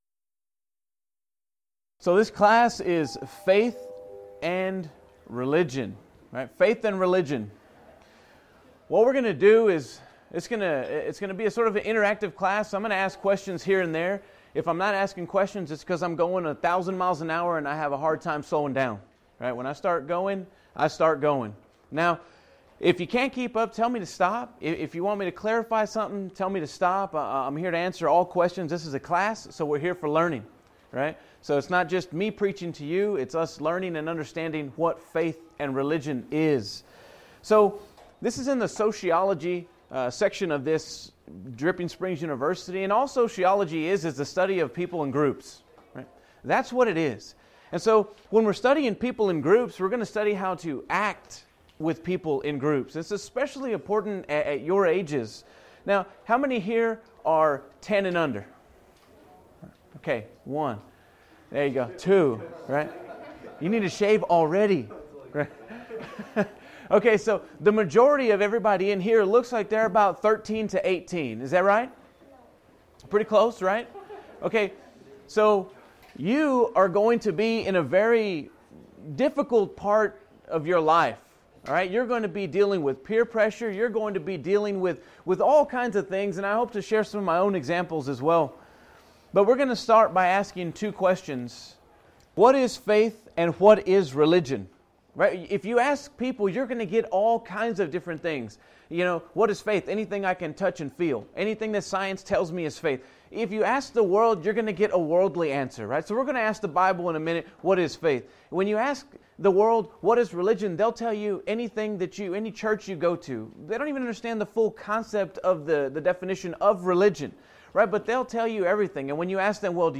Alternate File Link File Details: Series: Discipleship University Event: Discipleship University 2013 Theme/Title: Dead or Alive: Lessons about faith from a man who served Jesus.
Youth Sessions